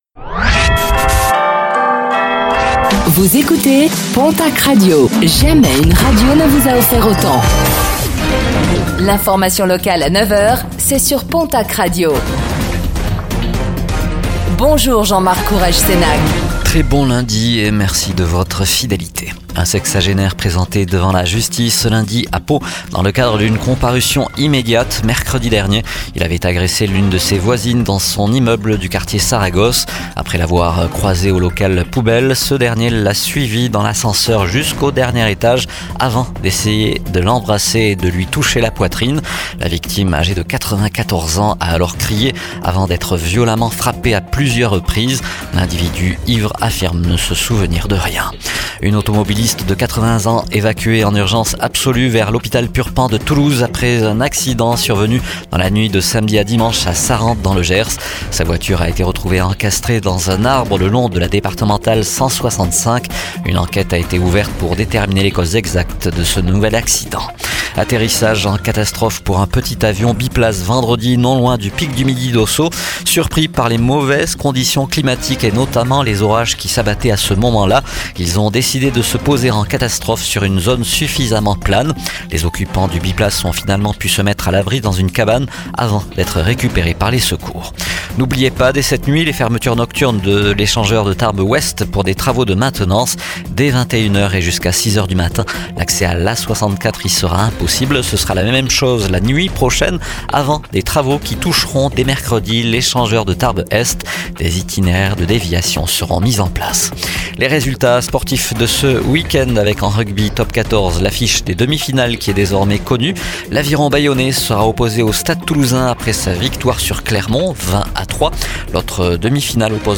Infos | Lundi 16 juin 2025